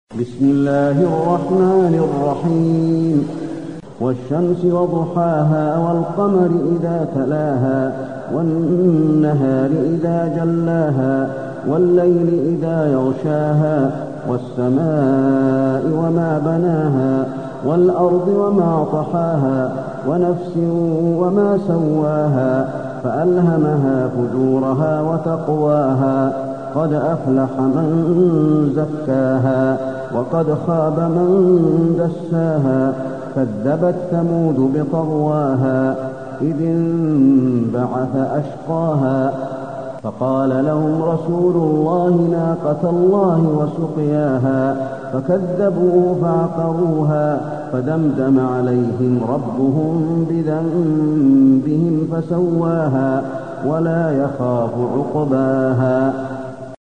المكان: المسجد النبوي الشمس